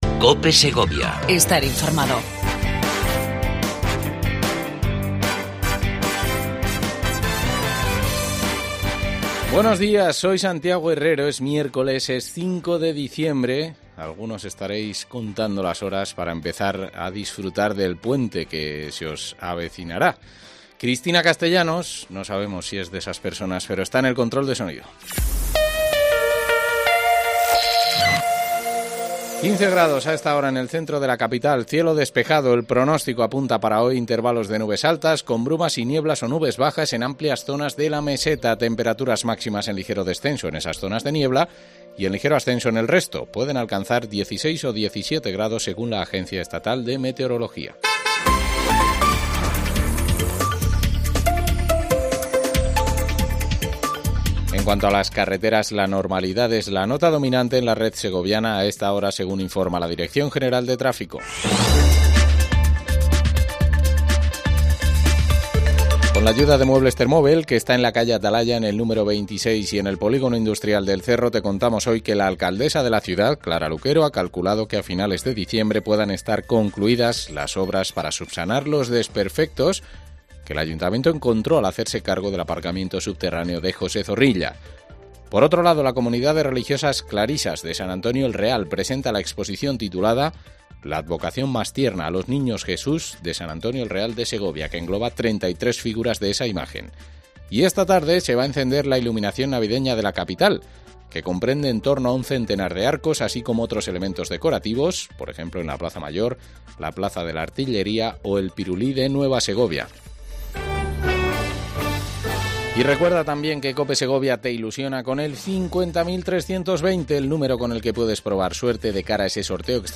AUDIO: Entrevista a Mario Pastor, Presidente del Consorcio Provincial de Medio Ambiente. Asamblea Área de Aportación.